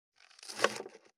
524切る,包丁,厨房,台所,野菜切る,咀嚼音,
効果音